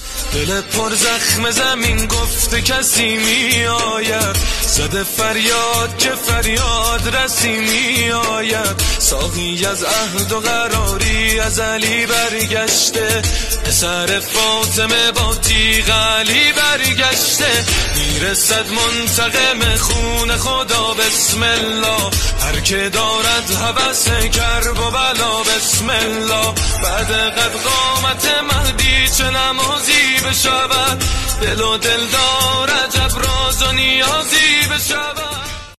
Islamic Ringtones